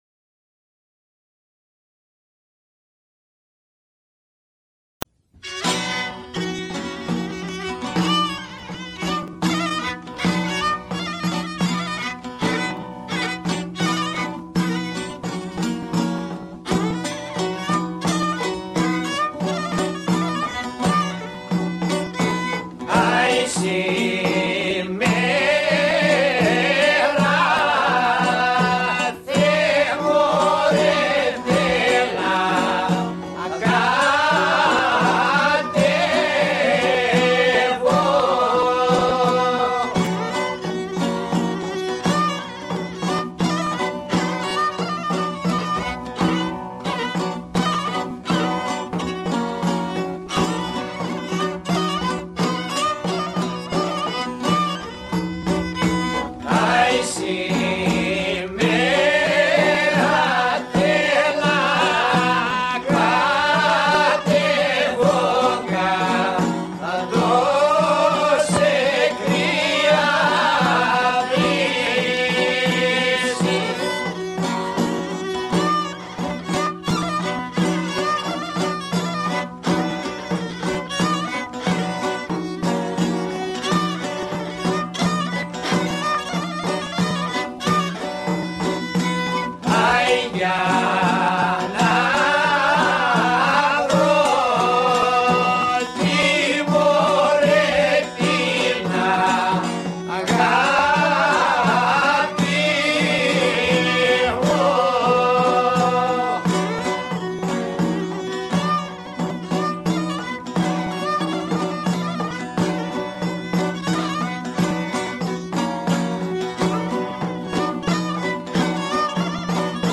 The dance has 2 parts, both slow, but with different rhythms.  The first is in three – 1,2,  3;   1,2,  3;  Slow, Quick, Slow, Quick.  The second part is in four – 1,2, 3, 4; 1,2,  3, 4;  Slow, Quick,Quick;Slow, Quick, Quick.